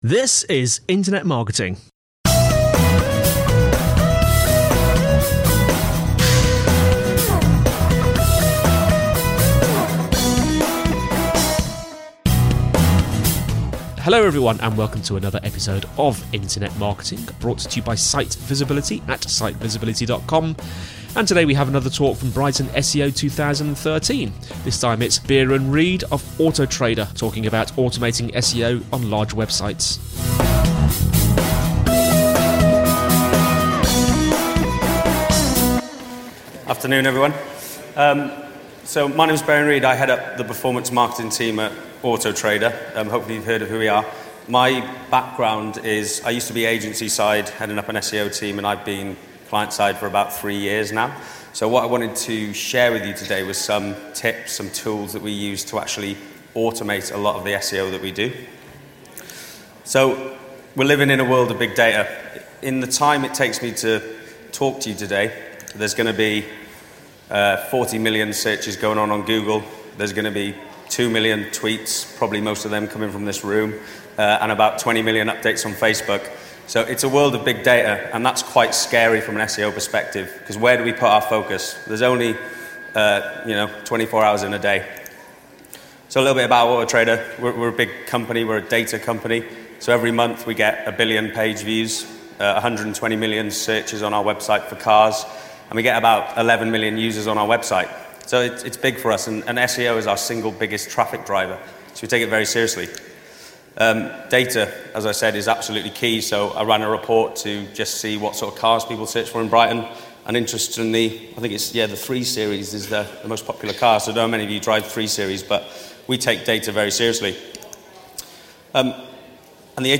This week’s internet marketing podcast is based on a talk from April’s BrightonSEO.